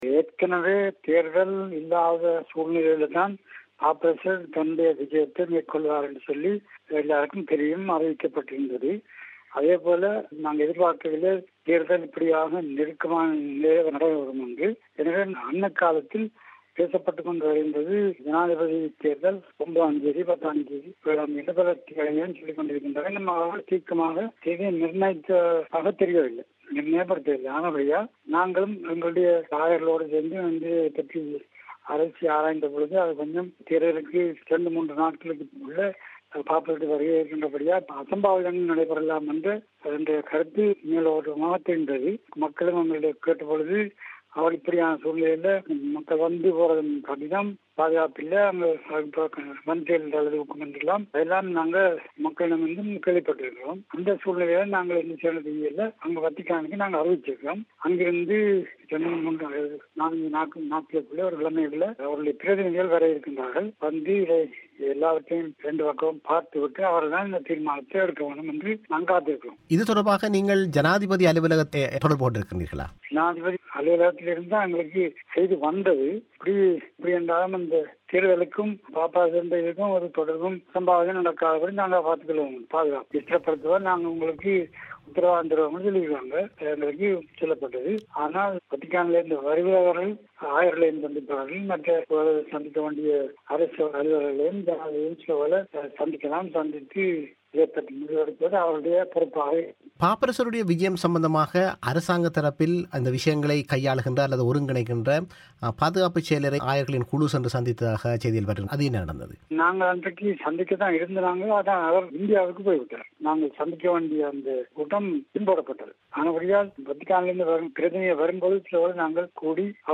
இவை குறித்த ஆயர் சுவாம்பிள்ளை அவர்களின் பிபிசி தமிழோசைக்கான செவ்வியை நேயர்கள் இங்கு கேட்கலாம்.